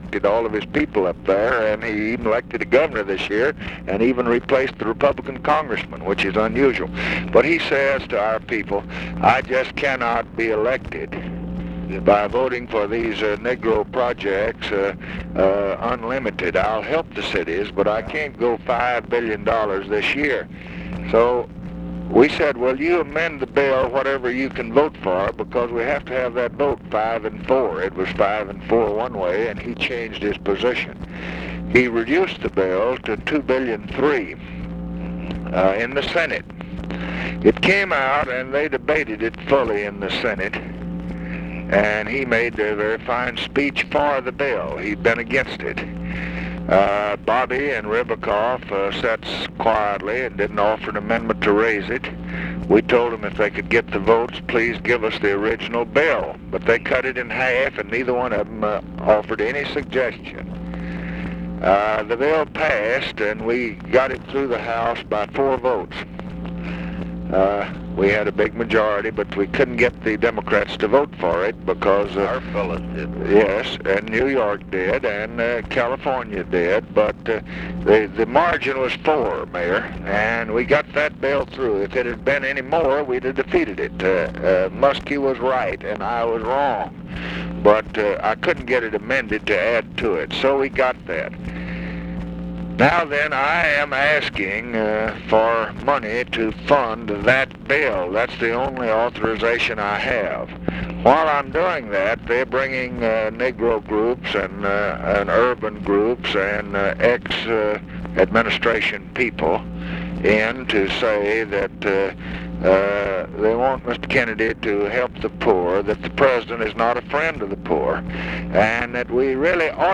Conversation with RICHARD DALEY, December 14, 1966
Secret White House Tapes